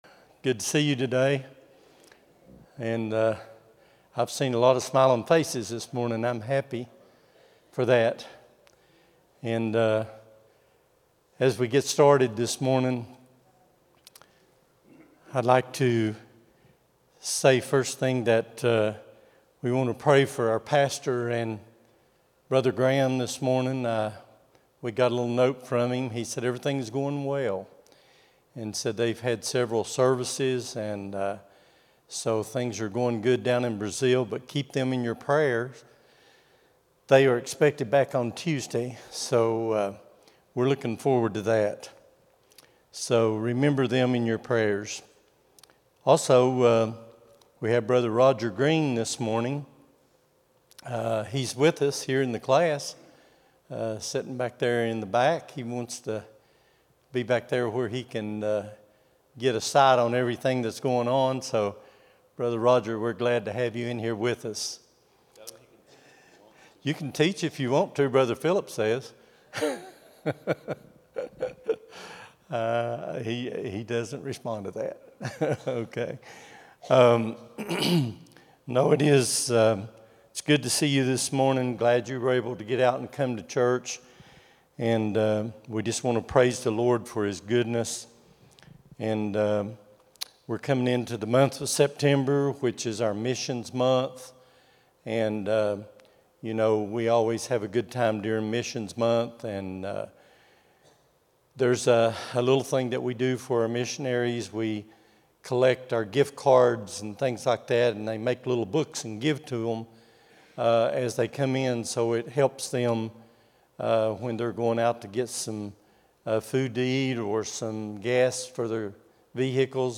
08-24-25 Sunday School | Buffalo Ridge Baptist Church
Sunday School lesson
at Buffalo Ridge Baptist Church in Gray, Tn.